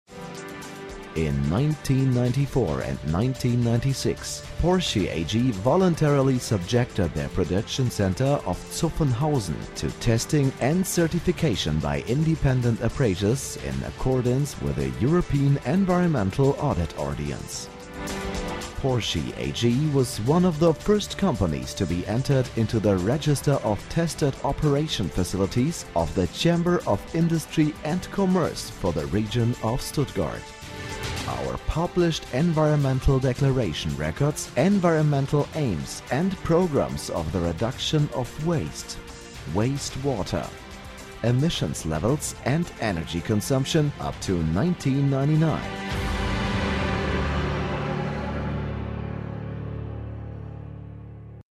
deutscher Profi-Sprecher. TV, Radio, Werbung, Synchron
Kein Dialekt
Sprechprobe: Sonstiges (Muttersprache):
german voice over artist